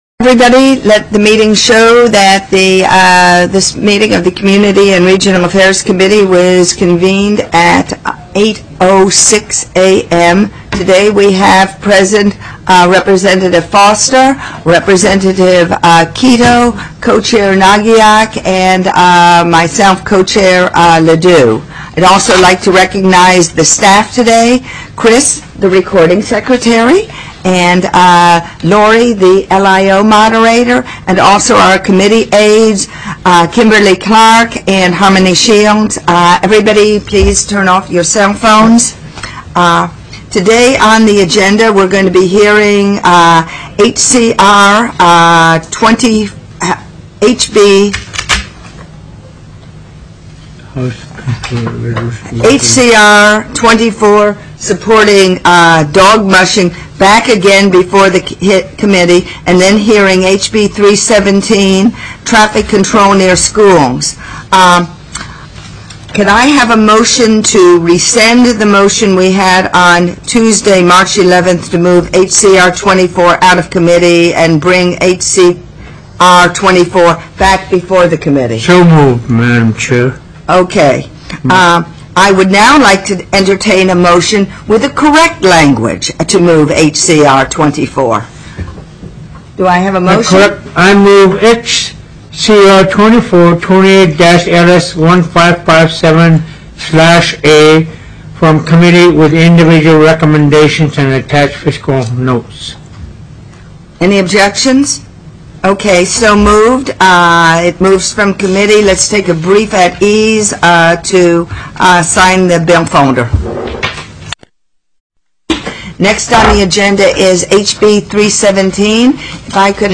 HB 317 TRAFFIC CONTROL DEVICES NEAR SCHOOLS TELECONFERENCED